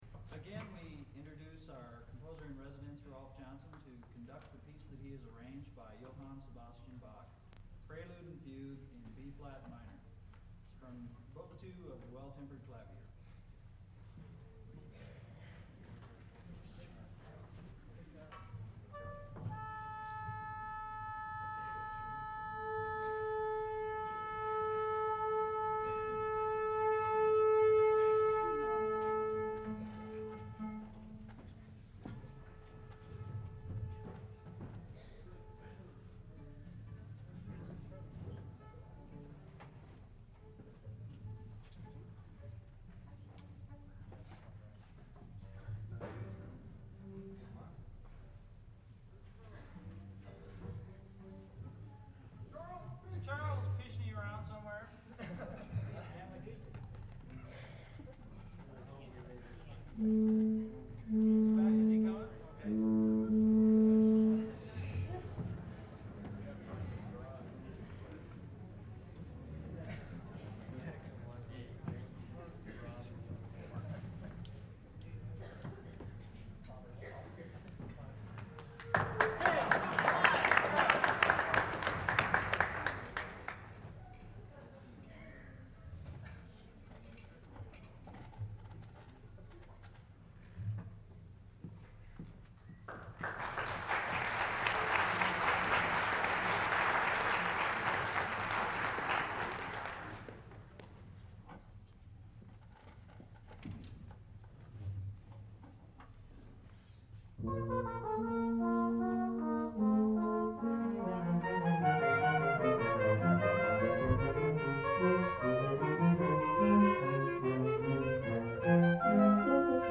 Concert Performance March 26, 1974
using a half-track, 10” reel-to-reel Ampex tape recorder.
Armstrong Auditorium, Sunday at 4:00 PM